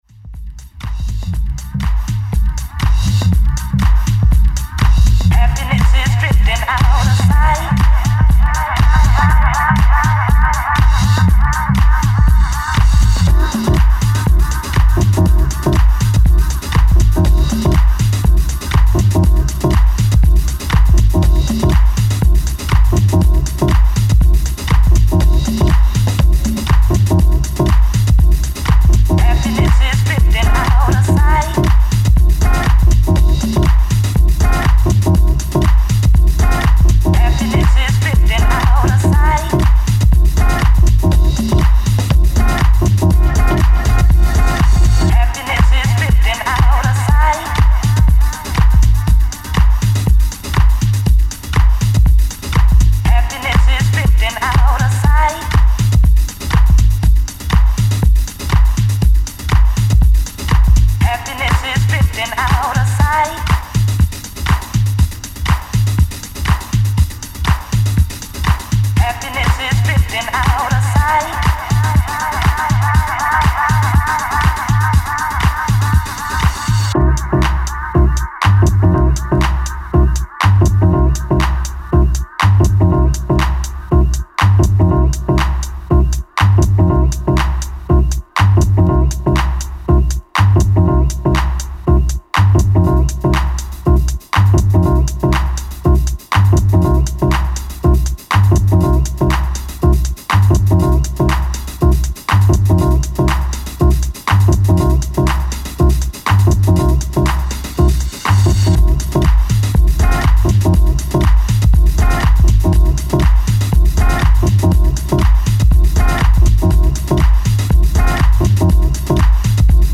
黒く熱いダンスミュージックMix CD！！